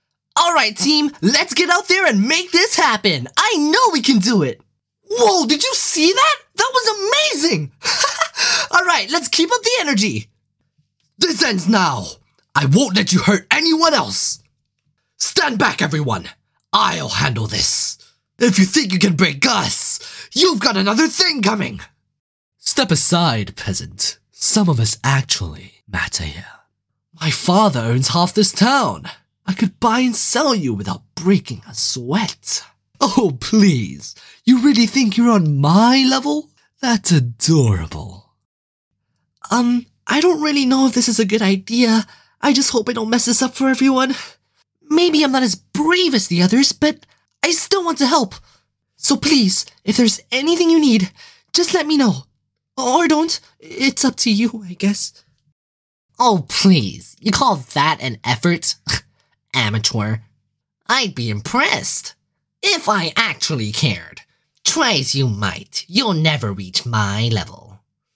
Exceptionally creative, possess excellent communication skills with clear concise pronunciation
Male
Character Announcer Voice Prompt
Energetic Young Corporate Smooth Conversational
Showreel